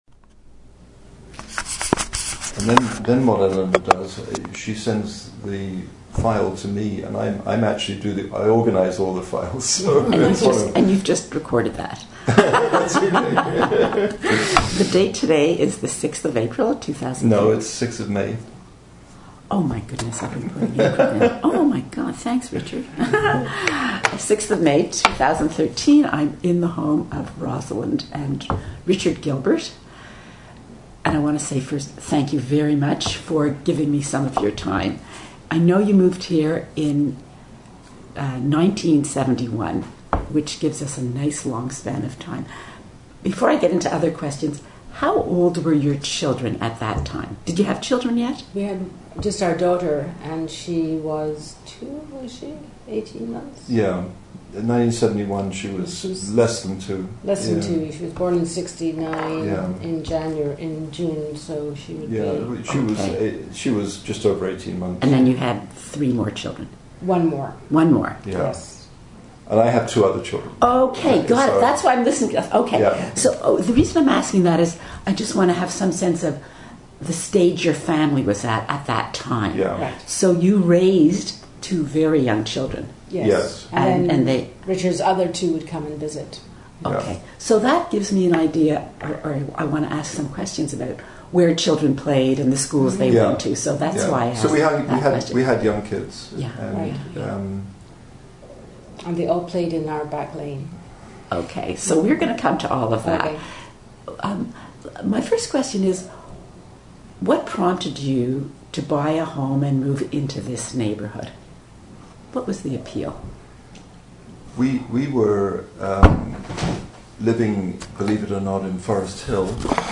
Harbord Village Oral History Project 2013